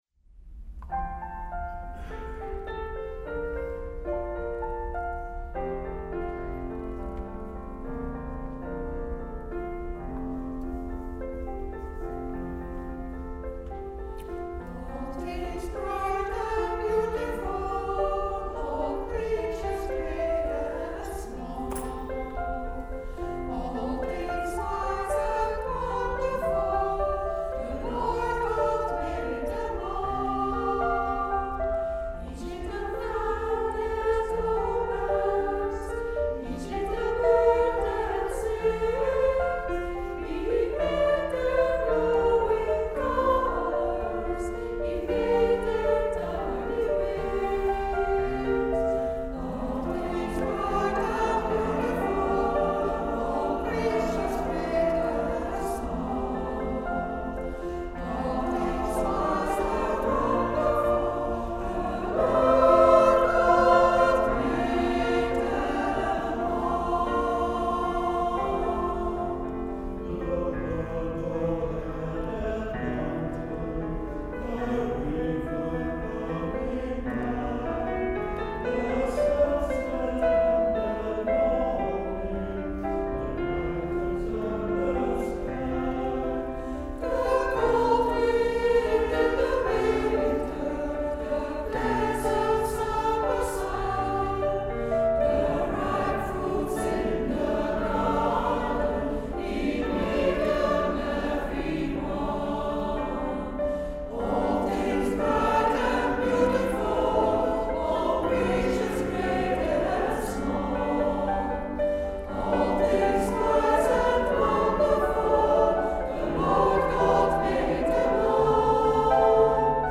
Welkom op de luisterpagina van Koor Blij Rondeel uit Heverlee - Leuven
Concert Falling in Love en CUERDAS
Sint-Franciscuskerk Heverlee zaterdag 17 februari 2018
De meeste computer-boxen klinken nogal blikkerig.